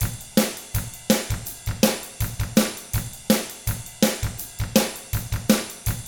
164ROCK T7-L.wav